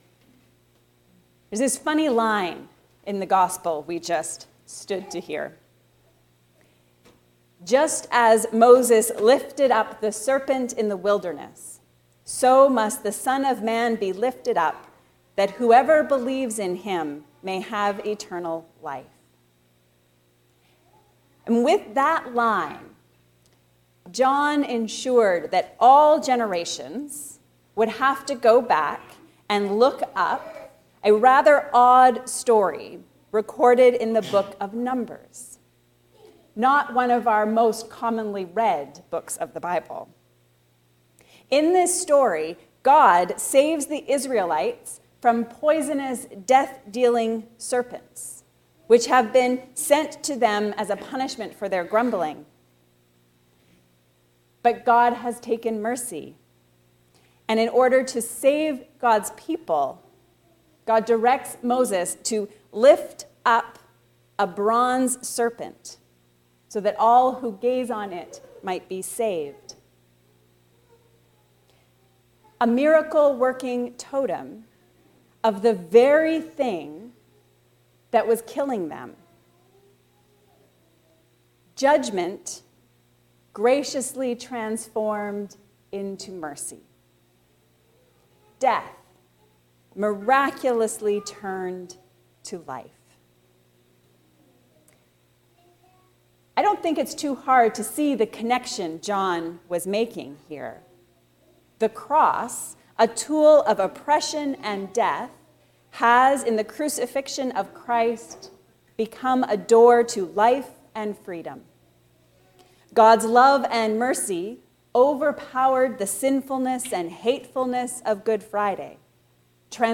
Step into the healing light. A sermon on John 3:1-21